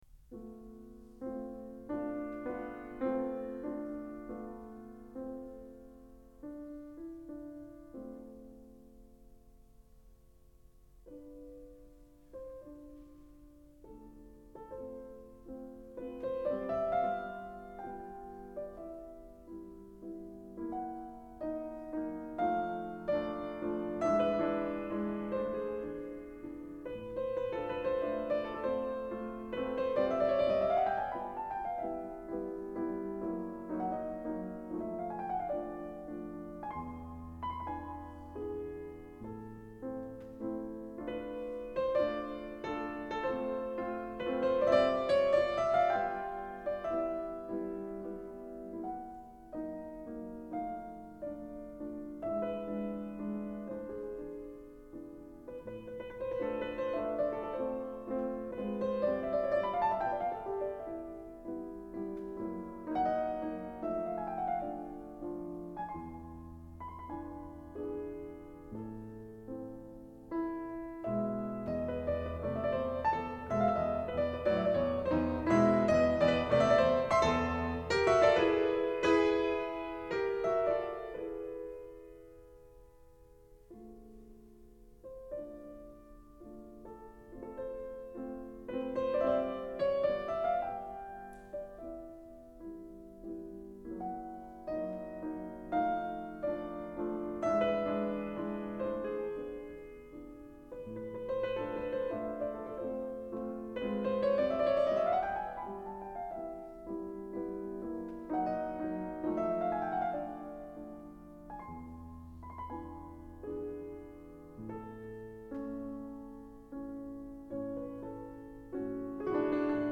Fu Ts’ong 1984 – Frédéric Chopin mazurka en la mineur Opus 17 n°4